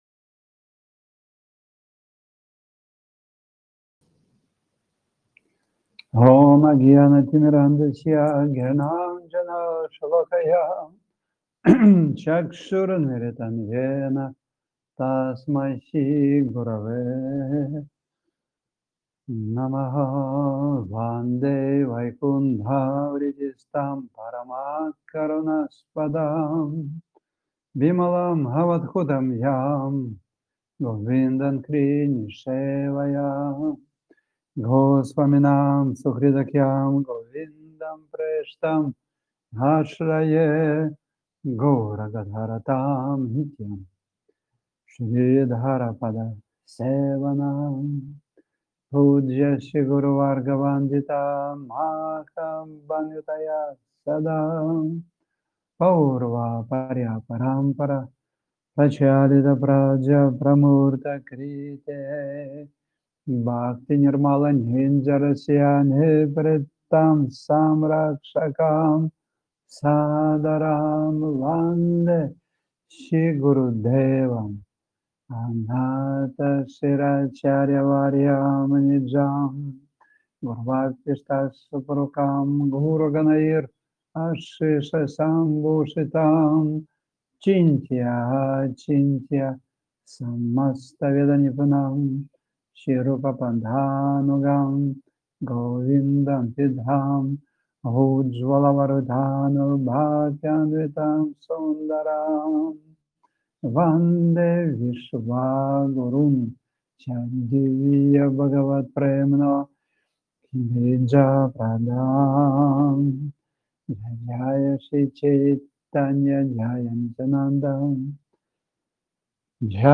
Пури Дхама, Индия